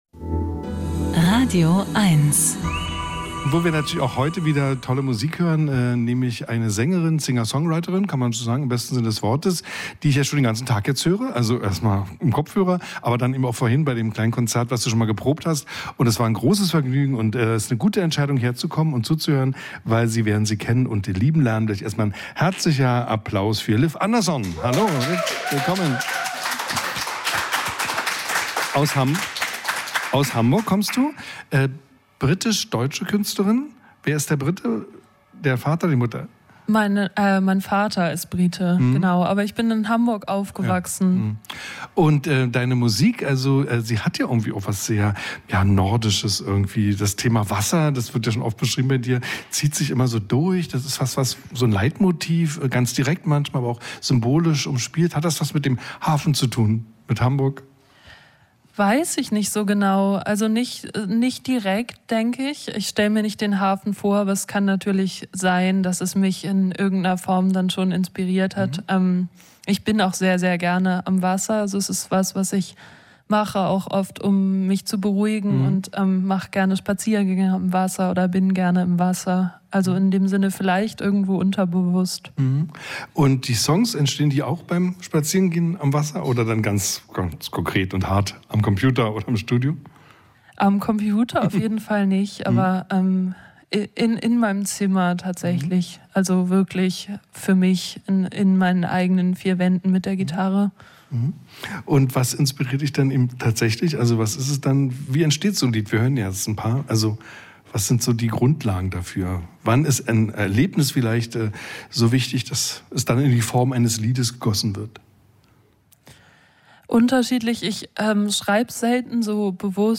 Musik-Interviews
Die besten Musikerinnen und Musiker im Studio oder am Telefon gibt es hier als Podcast zum Nachhören.